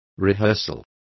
Complete with pronunciation of the translation of rehearsal.